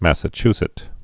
(măsə-chsĭt, -zĭt)